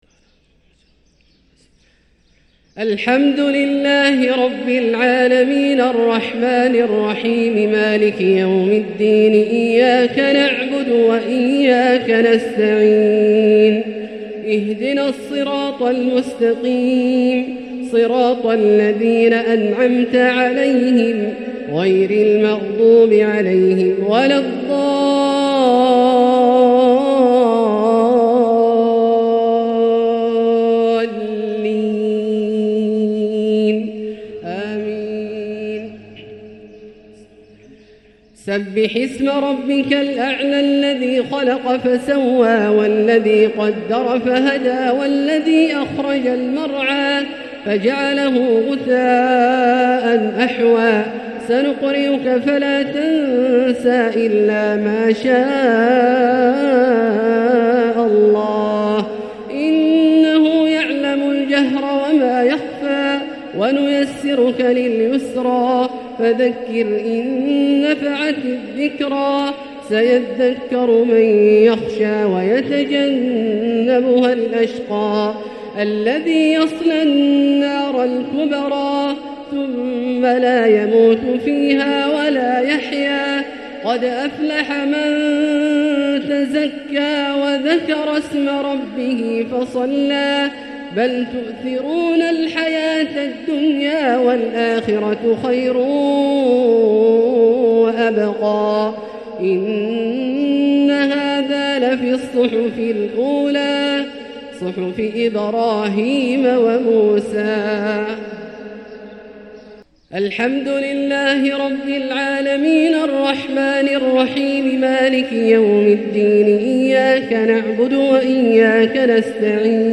تلاوة لسورتي الأعلى والغاشية | صلاة الجمعة 9-2-1445هـ > ١٤٤٥هـ > الفروض - تلاوات عبدالله الجهني